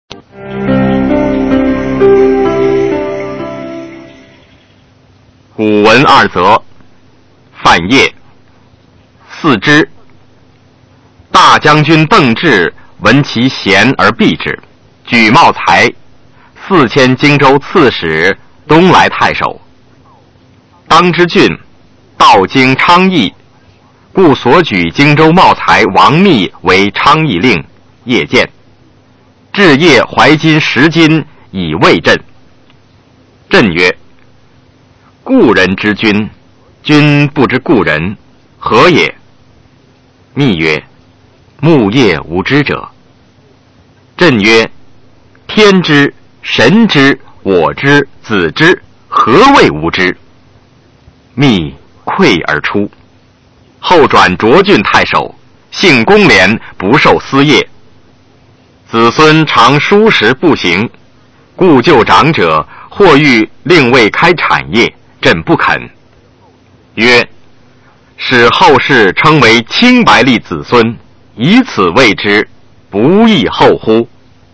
《四知》原文与译文（含在线朗读）